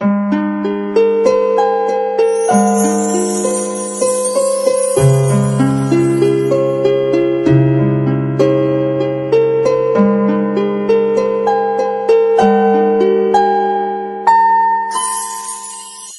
soft_harp.ogg